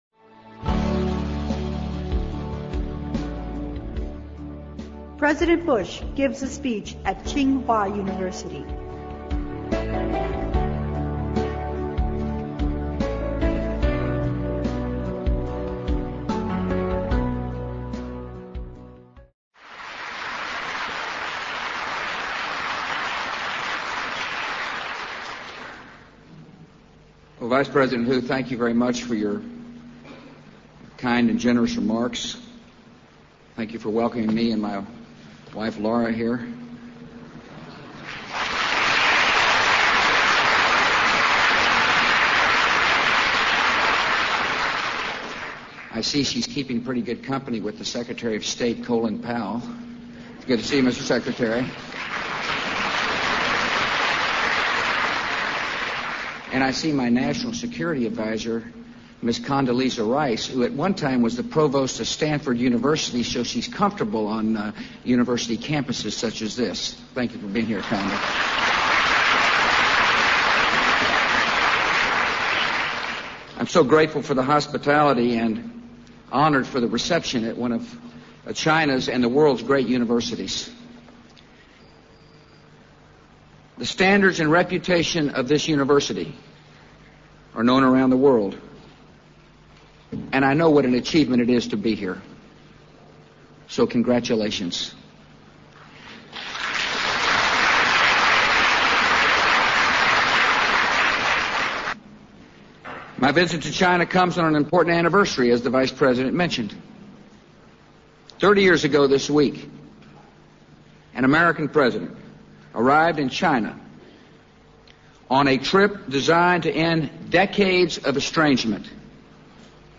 President Bush Gives a Speech at Tsinghua University 听力文件下载—在线英语听力室